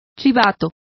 Complete with pronunciation of the translation of telltales.